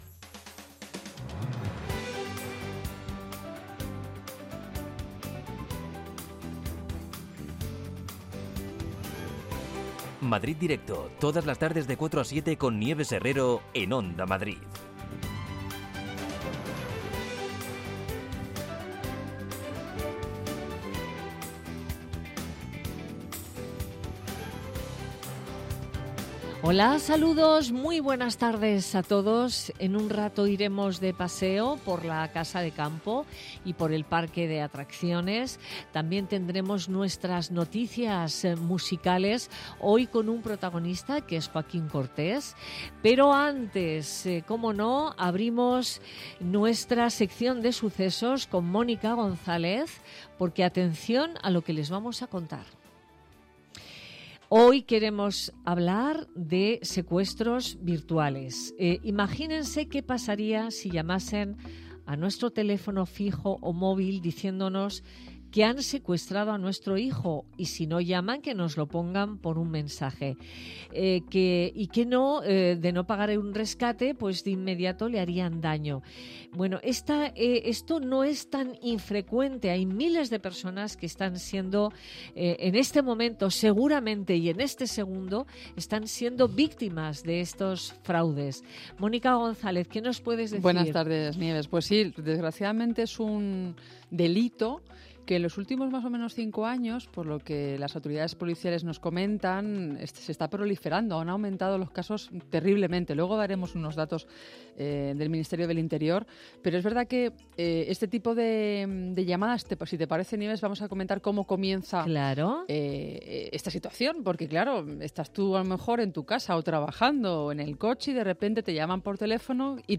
Nieves Herrero se pone al frente de un equipo de periodistas y colaboradores para tomarle el pulso a las tardes. Tres horas de radio donde todo tiene cabida: análisis de la actualidad cultural, ciencia, economía... Te contamos todo lo que puede preocupar a los madrileños.